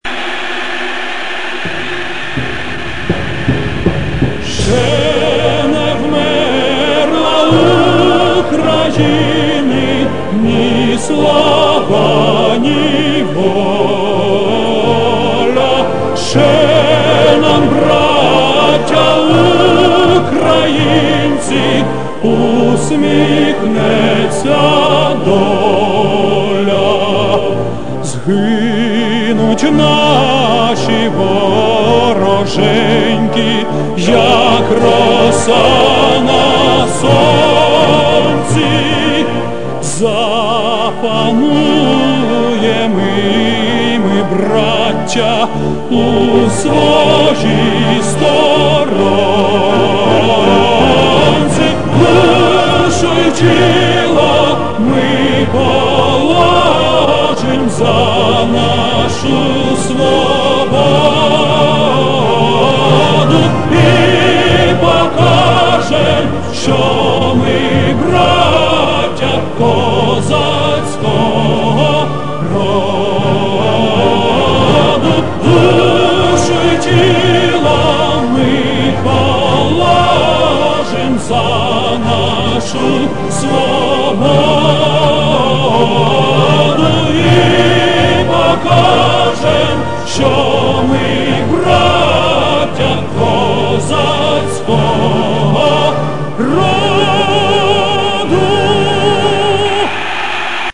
Павло Чубинський (музика - М.Вербицький)
Исполняет Александр Пономарев
Исполняет Александр Пономарев перед матчем Украина-Германия (10.11.2001)